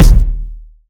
Kicks
KICK.124.NEPT.wav